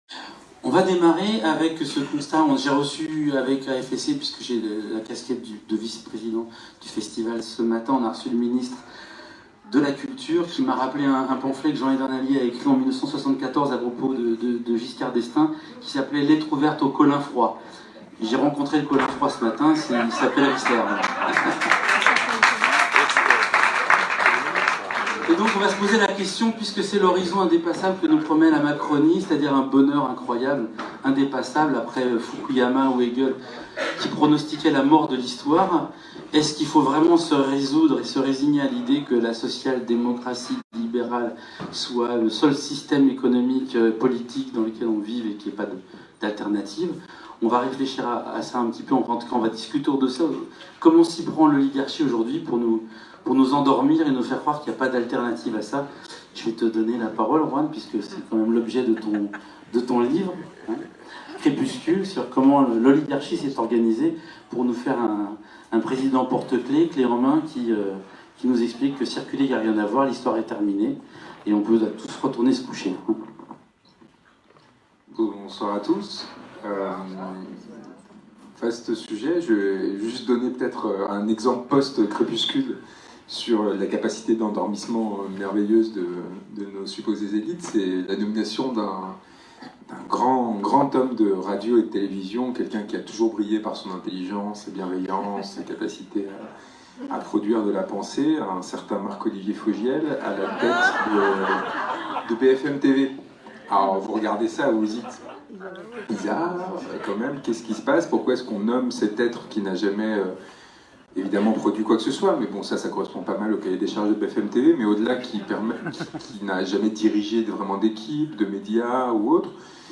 Juan-Branco-débat-avec-François-Bégaudeau-à-Avignon-128-kbps.mp3